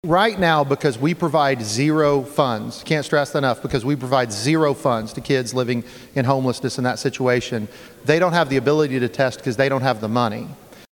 CLICK HERE to listen to commentary from Representative John Echols.
Senate Bill 358 aims to remove the need for a student to attend a year in public school before being eligible for the scholarship. Bill Sponsor Representative John Echols explains why some students should be exempt from testing.